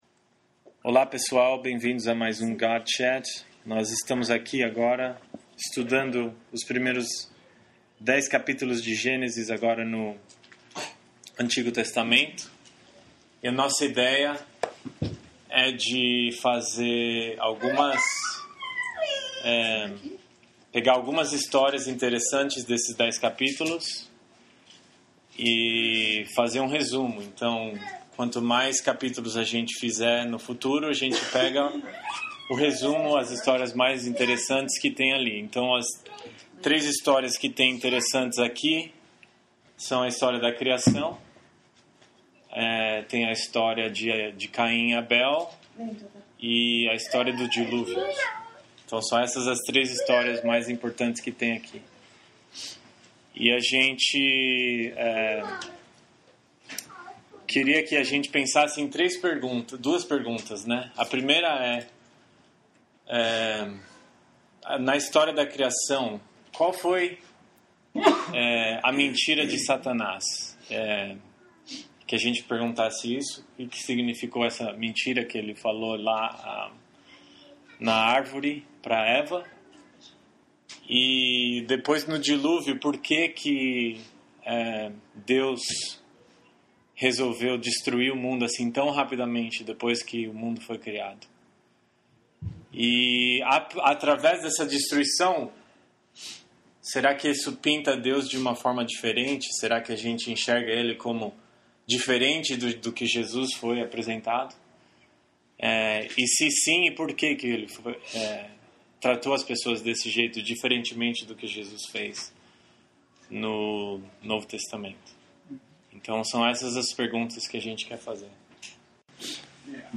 This is our first dialogue in the book of Genesis.